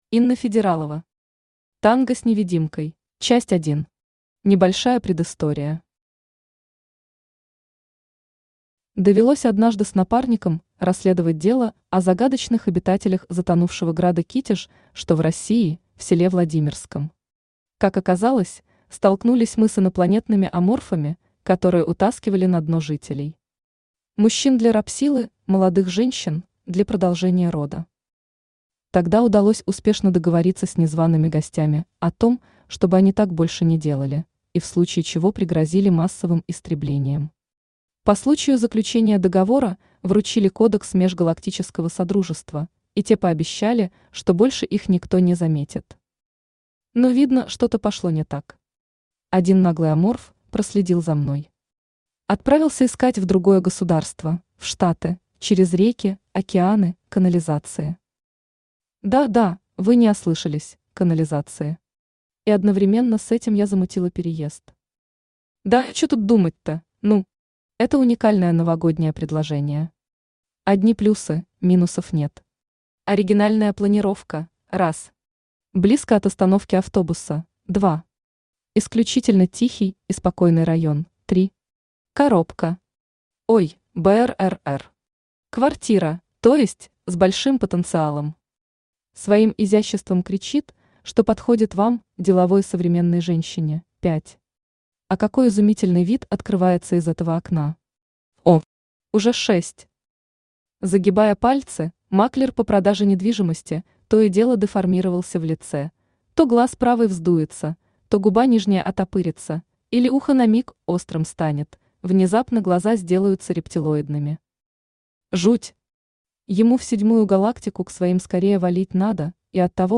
Аудиокнига Танго с невидимкой | Библиотека аудиокниг
Aудиокнига Танго с невидимкой Автор Инна Федералова Читает аудиокнигу Авточтец ЛитРес.